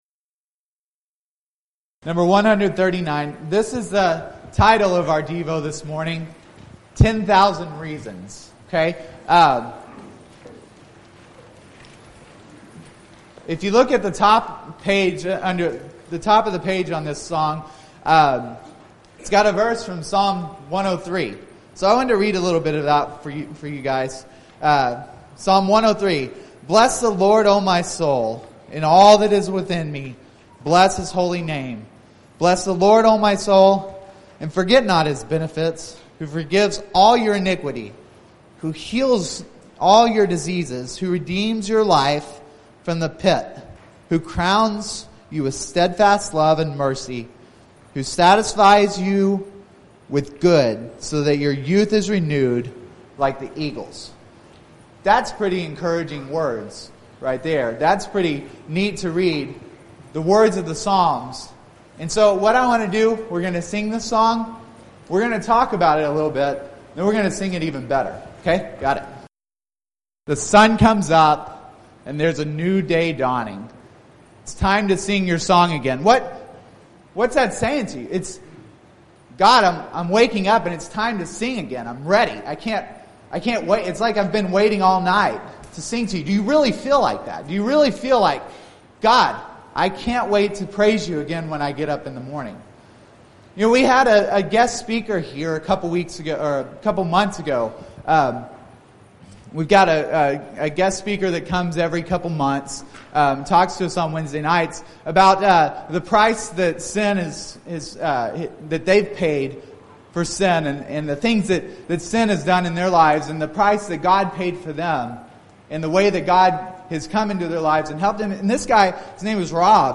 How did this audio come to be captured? Event: 2015 Discipleship University